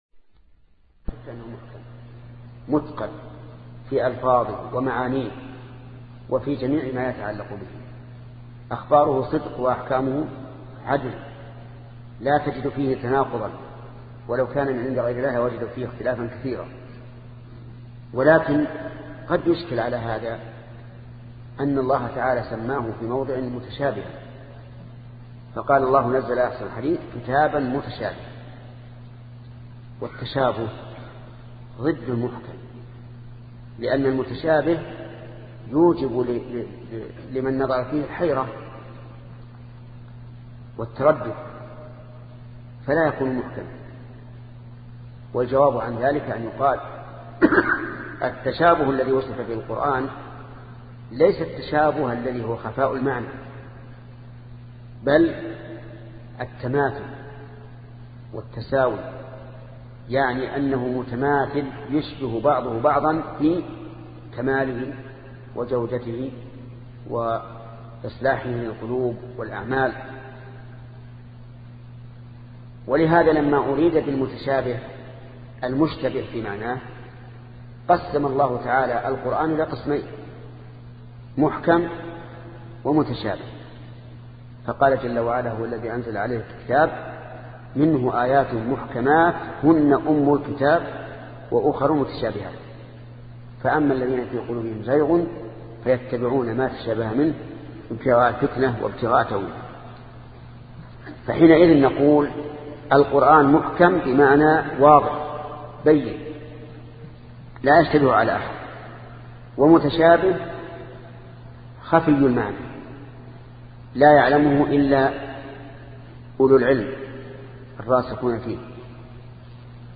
سلسلة مجموعة محاضرات شرح العقيدة السفارينية لشيخ محمد بن صالح العثيمين رحمة الله تعالى